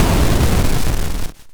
explosionCrunch_003.ogg